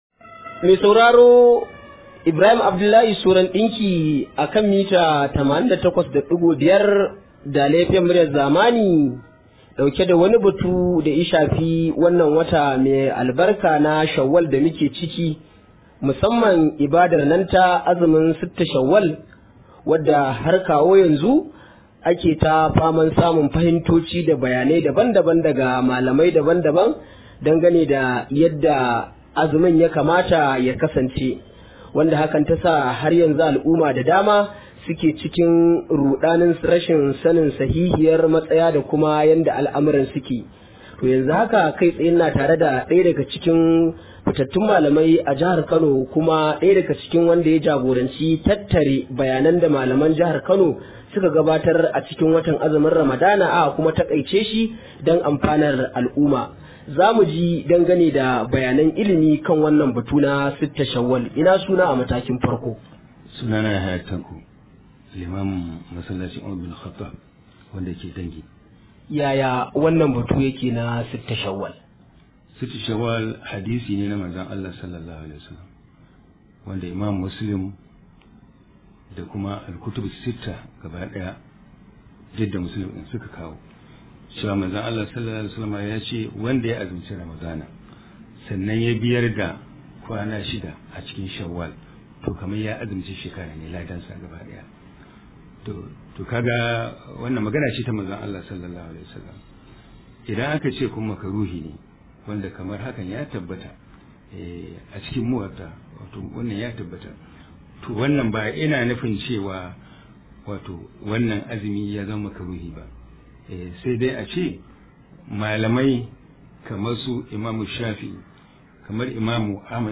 Rahoto